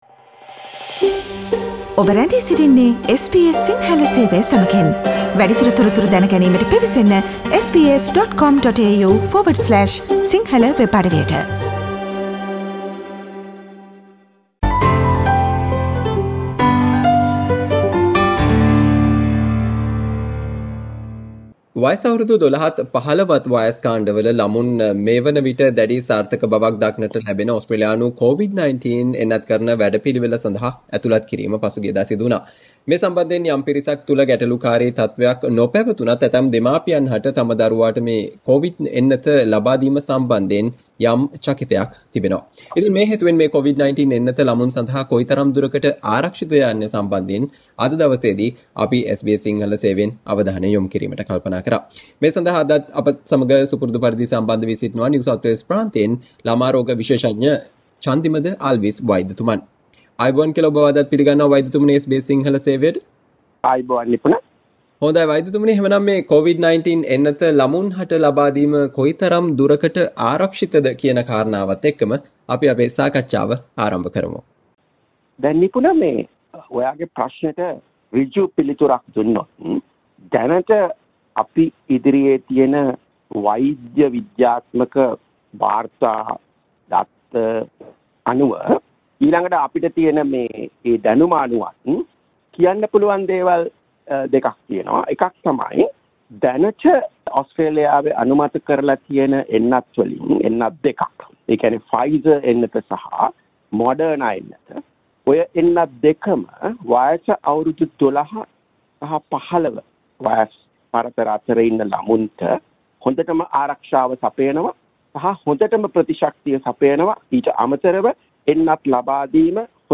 ඕස්ට්‍රේලියානු COVID-19 එන්නතකරණ වැඩපිළිවෙල සඳහා ඇතුලත් කල වයස අවුරුදු 12-15 වයස් කාණ්ඩ වල ළමුන් සඳහා එන්නත් ලබාදීම කෙතරම් ආරක්‍ෂිතද යන්න සම්බන්ධයෙන් SBS සිංහල සේවය සිදු කල වෛද්‍ය සාකච්චාවට ඔබට මේ ඔස්සේ සවන් දිය හැක.